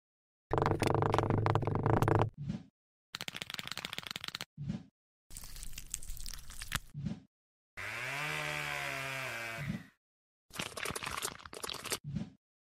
Brazilian Mp3 Sound Effect ⚽ Roberto Carlos ASMR, Brazilian Legend Whispers!